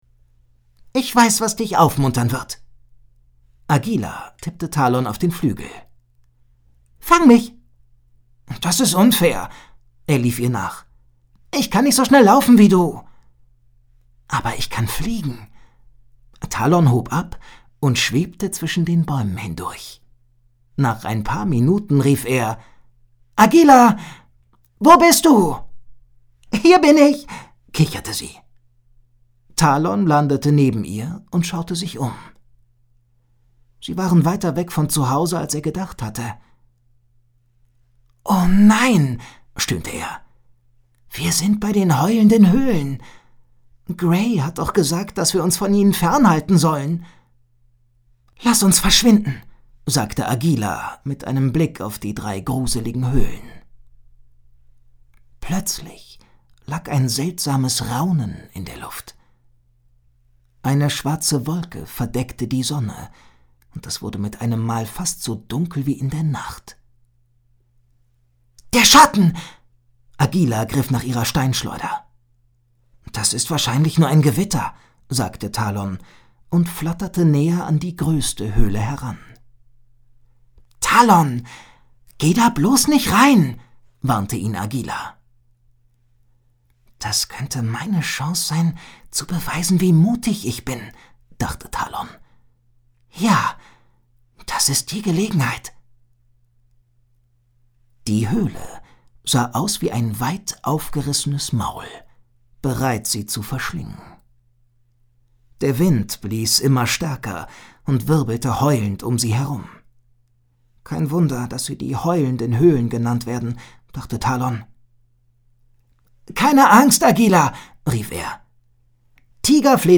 Hörbuch, 1 CD, ca. 50 Minuten